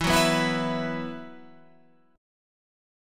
Esus2 chord